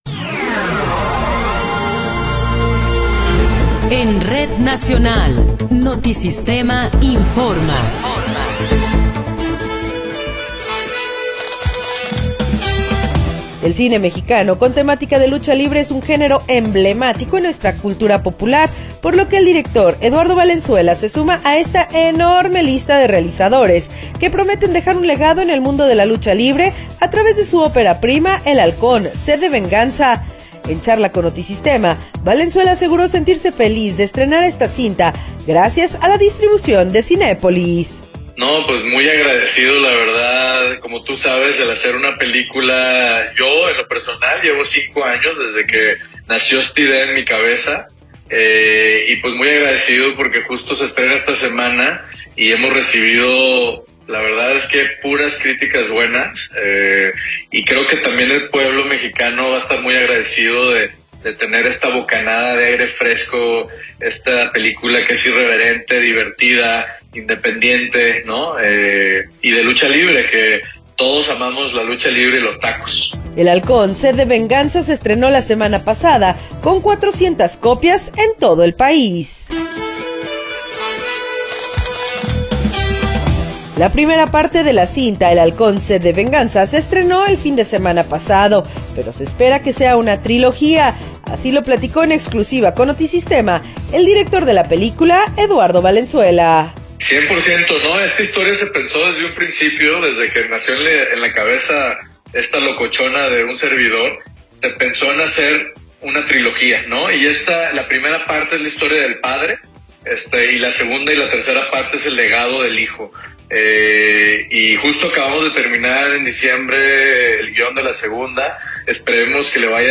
Noticiero 19 hrs. – 10 de Marzo de 2024
Resumen informativo Notisistema, la mejor y más completa información cada hora en la hora.